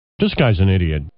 Category: Radio   Right: Both Personal and Commercial